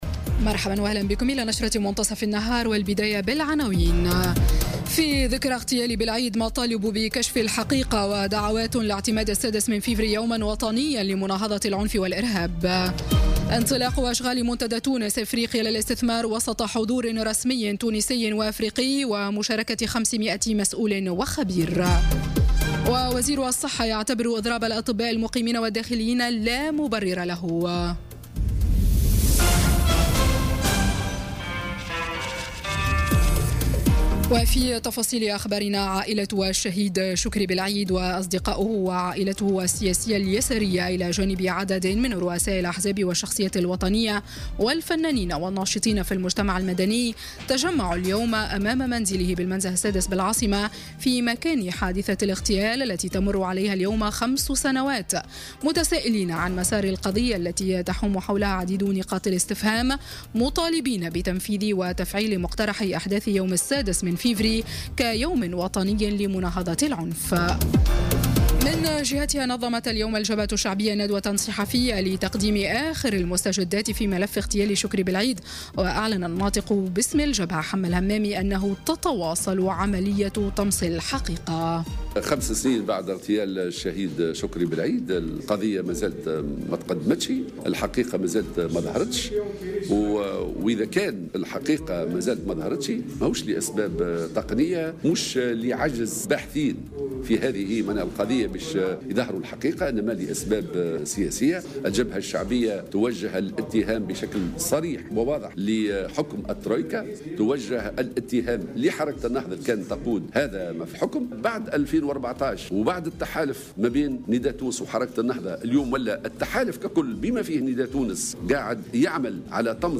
نشرة أخبار منتصف النهار ليوم الثلاثاء 6 فيفري 2018